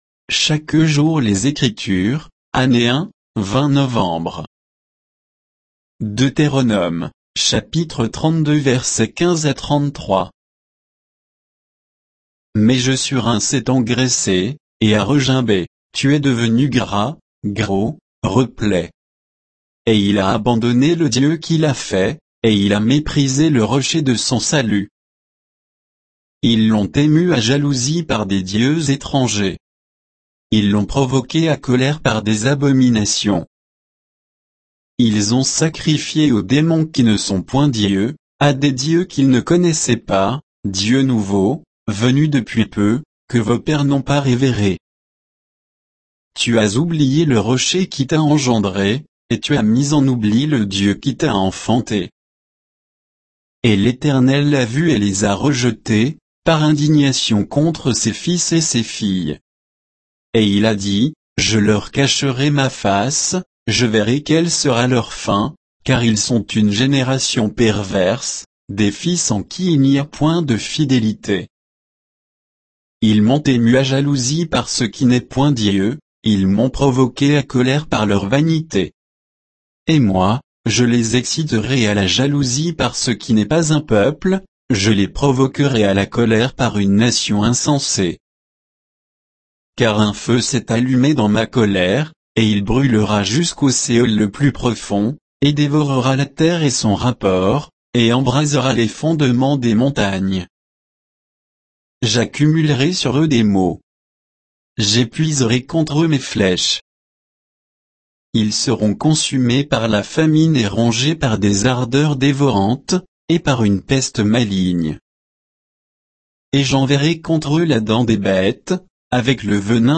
Méditation quoditienne de Chaque jour les Écritures sur Deutéronome 32